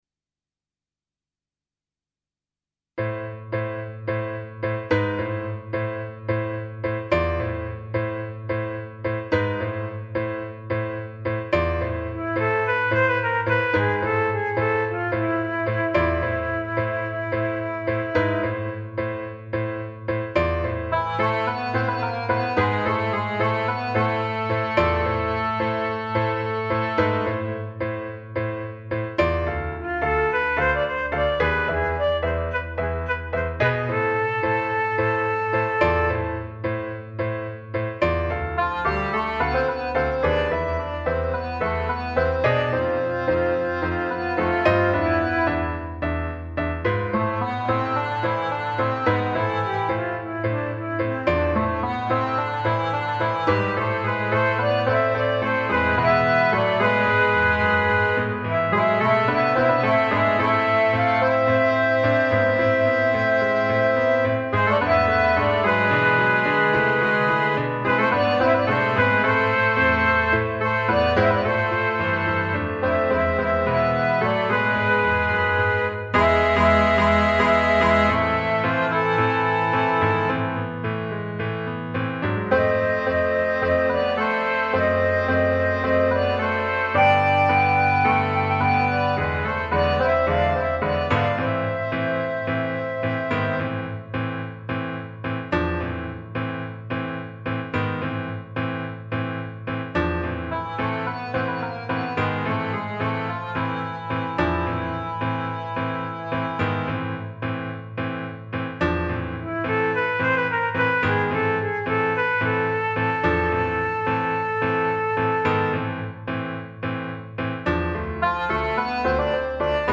Instrumentation: C, Bb, pno accompaniament
A duet and keyboard accompaniment arrangement